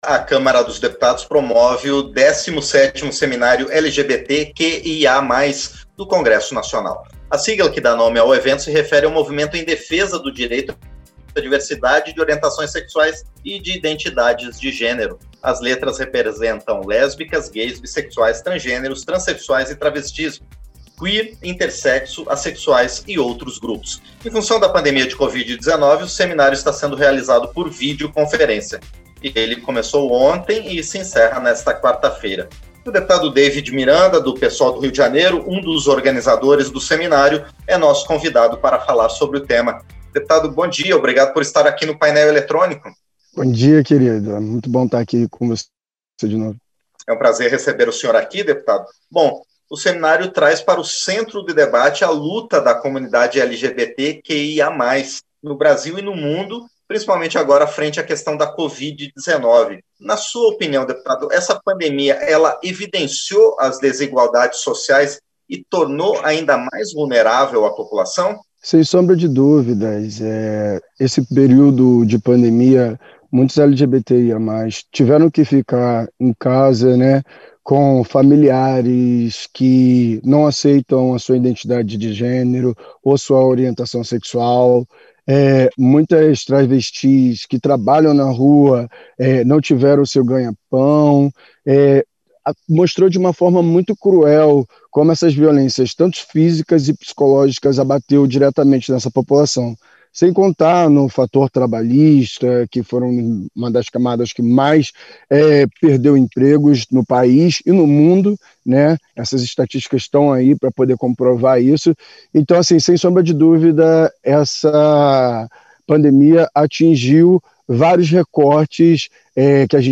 Entrevista - Dep. David Miranda (PSOL-RJ)